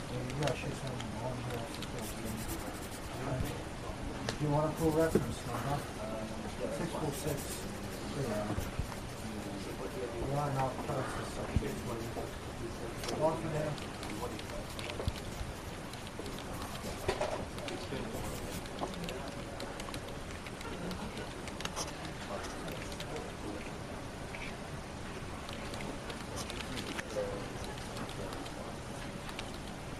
Free Ambient sound effect: Brown Noise.
Brown Noise
Brown Noise is a free ambient sound effect available for download in MP3 format.
408_brown_noise.mp3